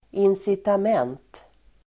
Ladda ner uttalet
Uttal: [insitam'en:t]